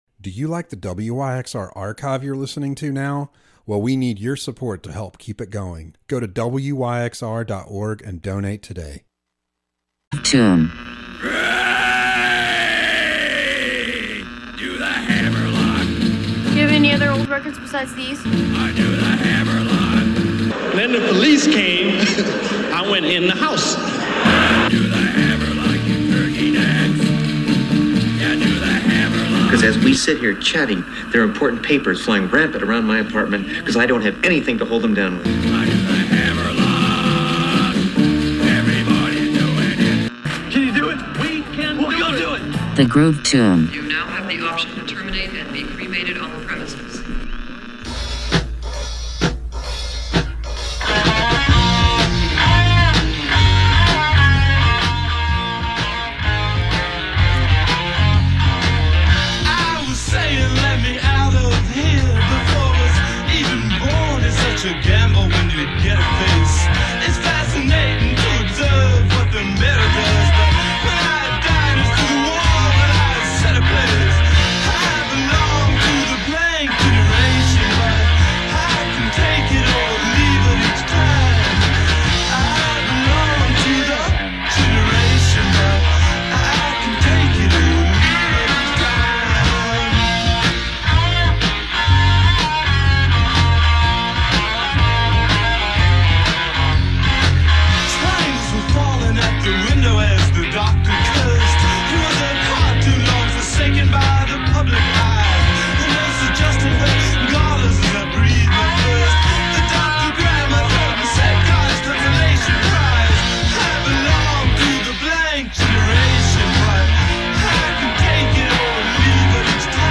Join us for a horror-tinged ride around the dial, exploring a seamless blend of jazz, blues, punk, soul, prog, rock and roll, and more. From the depths of the groovy underworld, "The Groove Tomb" brings you a late-night broadcast that's eerie, exhilarating, and always in the spirit of musical discovery.